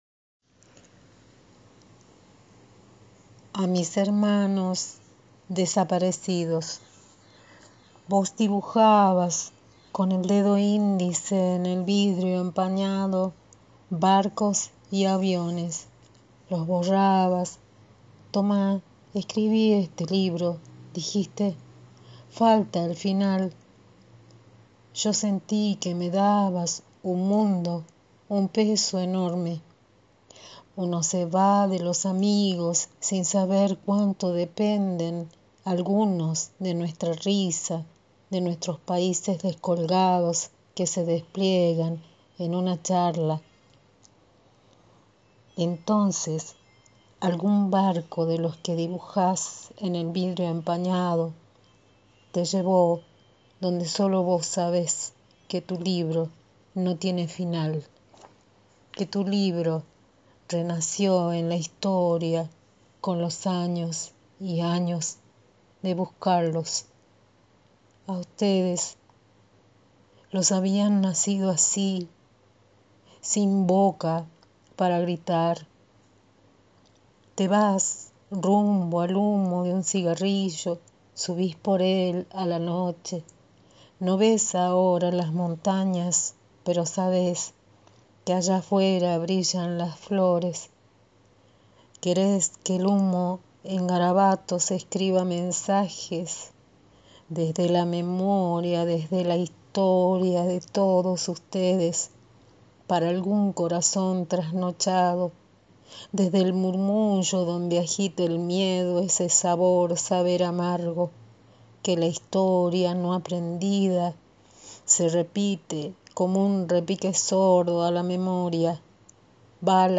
poema escrito y leído
Hoy 23 de marzo del 2020, en tiempos de la Pandemia, mientras en Argentina existe el propósito de cuidarnos como comunidad, donde ninguna persona es enemiga y la apuesta es por la vida, vamos marchando con la voz.